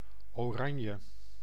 Ääntäminen
IPA: /oˈrɑɲə/